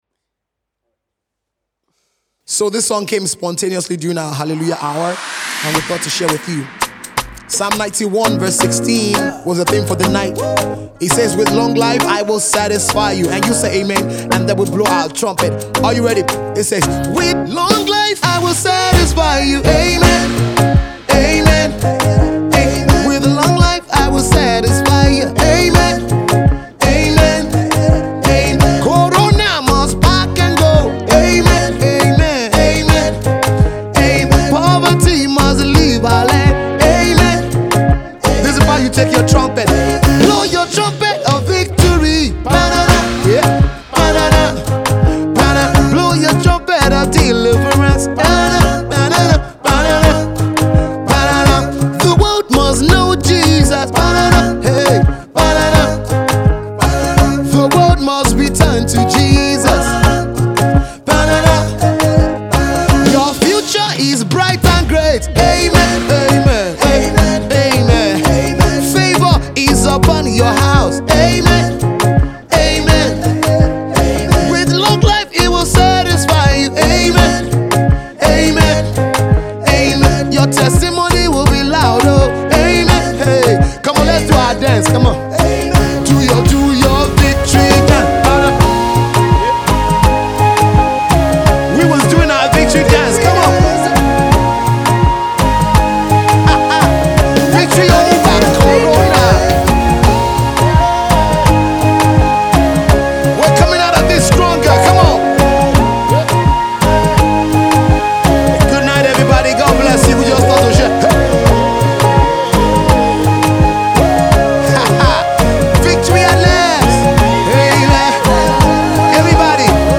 This is a PROPHETIC SONG.